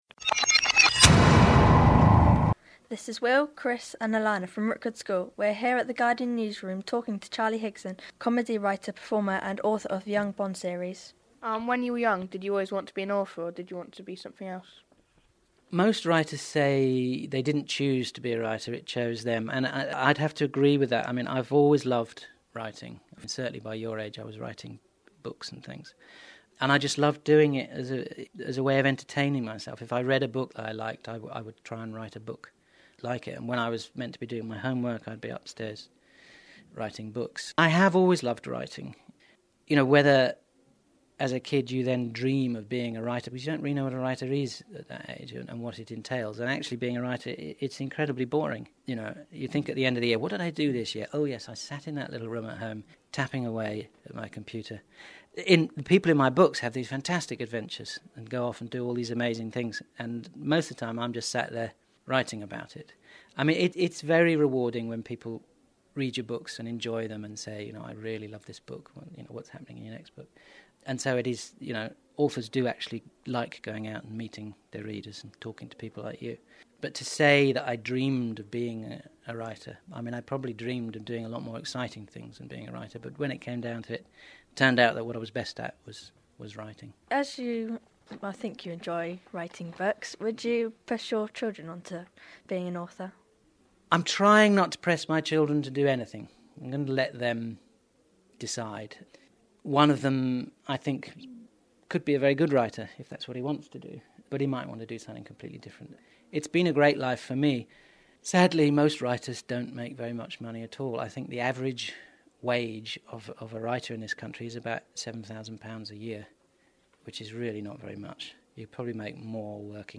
Charlie Higson interviewed at `Reading For Pleasure` conference